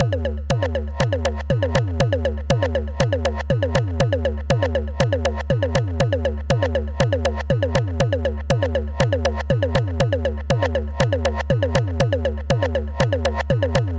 动力鼓环120BpM
描述：在学习dubstep时写下的相当酷的节奏。用 ableton live 8 编写。
Tag: 120 bpm Dubstep Loops Drum Loops 2.36 MB wav Key : Unknown